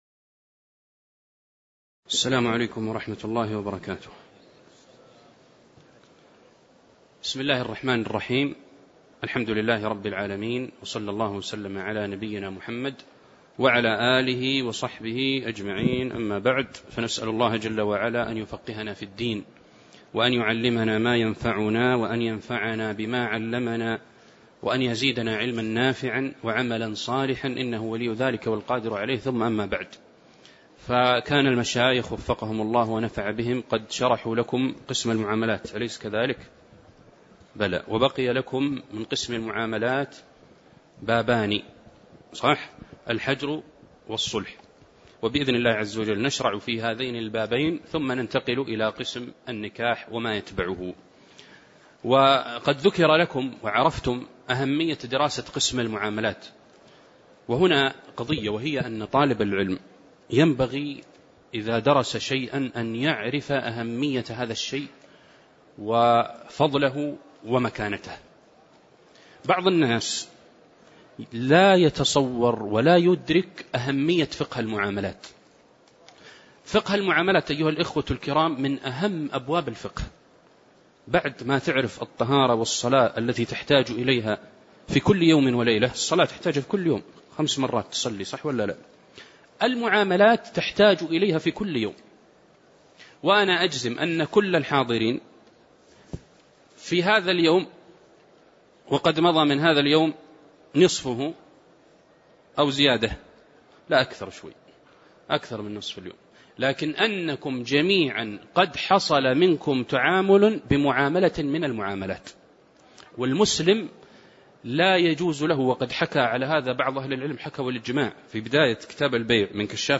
تاريخ النشر ١٨ شوال ١٤٣٧ هـ المكان: المسجد النبوي الشيخ